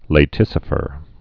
(lā-tĭsə-fər)